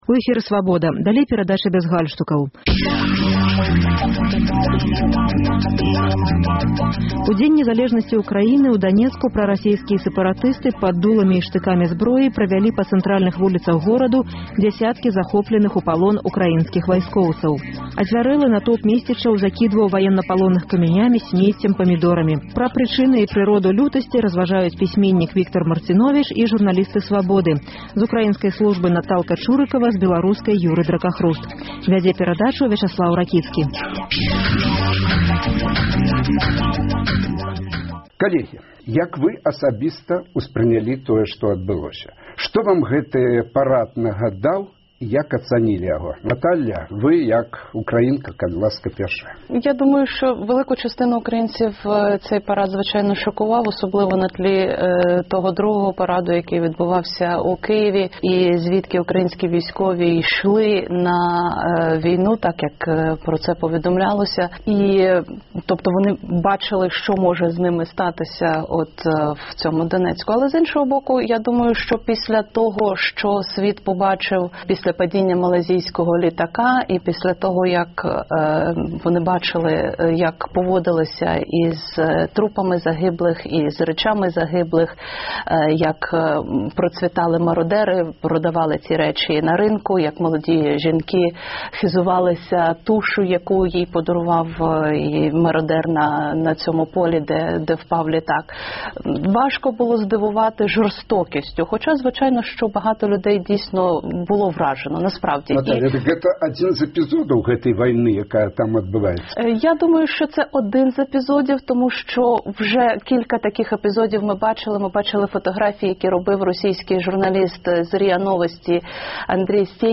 Пра прычыны і прыроду лютасьці разважаюць пісьменьнік Віктар Марціновіч і журналісты Свабоды: